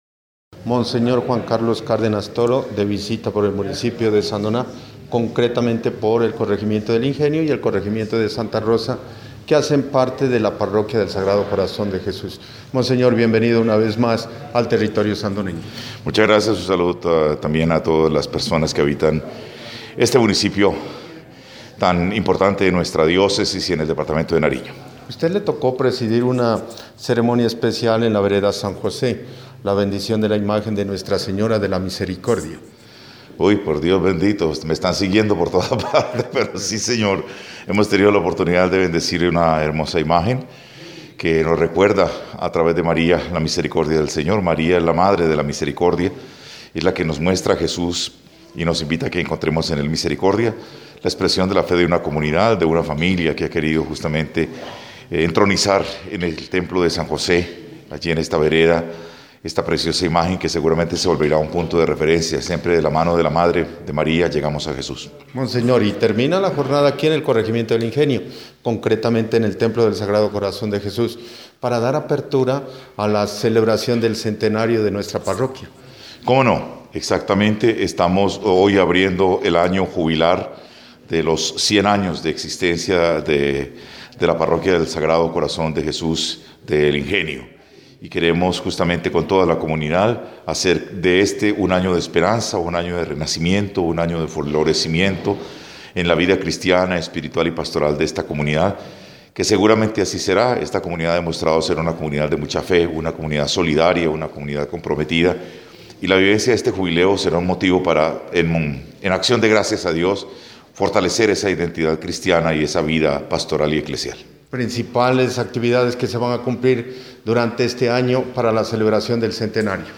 Entrevista con Monseñor Juan Carlos Cárdenas Toro: